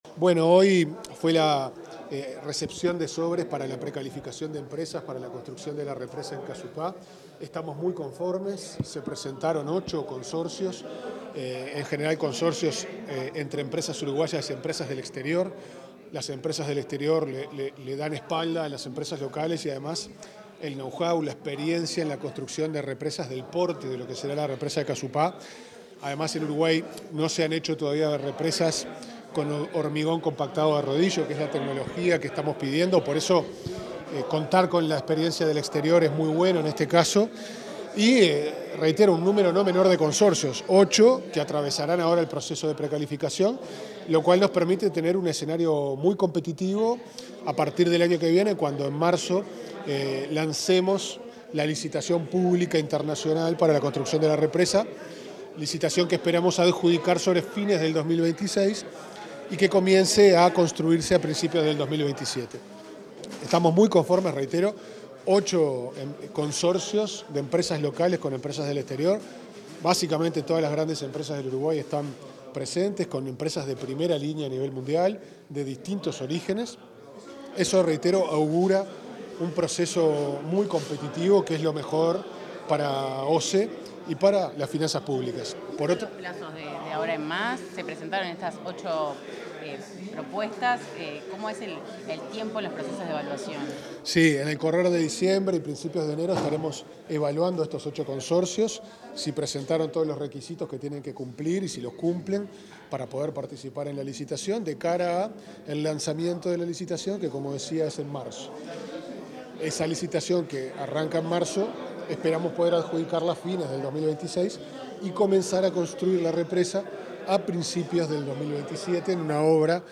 Declaraciones del presidente de OSE, Pablo Ferreri